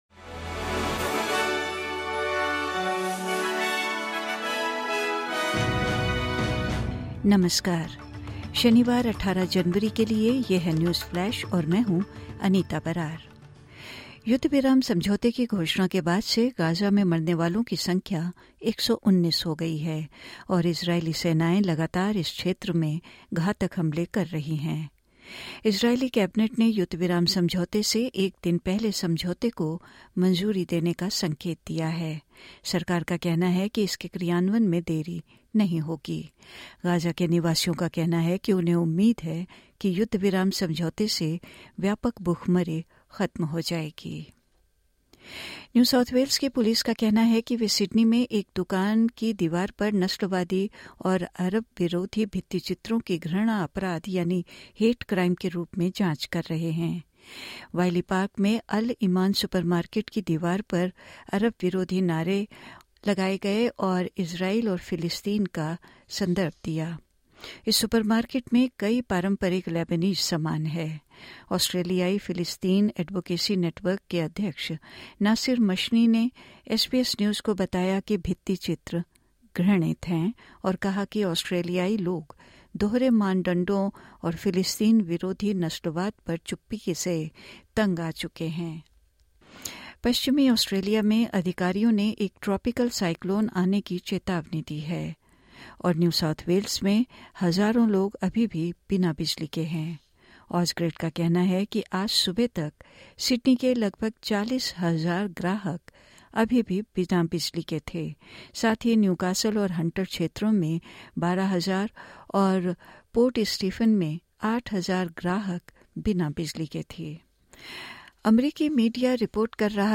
सुनें 18/01/2025 की प्रमुख खबरें ऑस्ट्रेलिया और भारत से हिन्दी में।